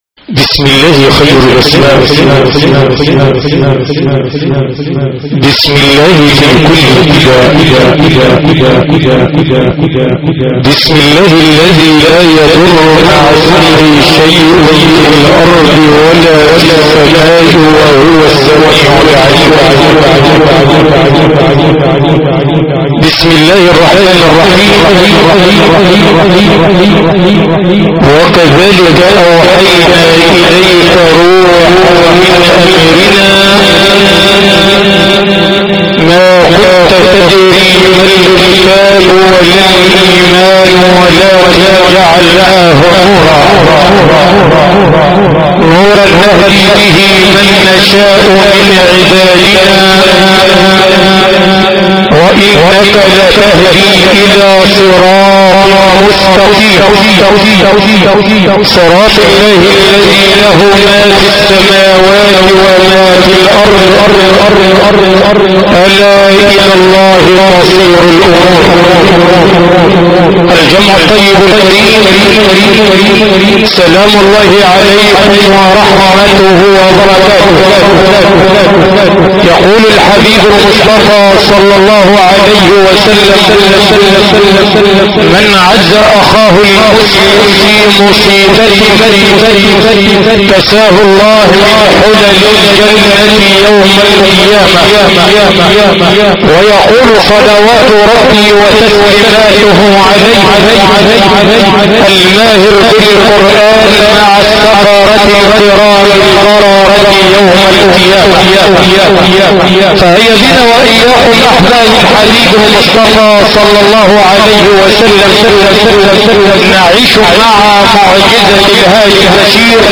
باقة من تلاوات القارئ حجاج الهنداوي 1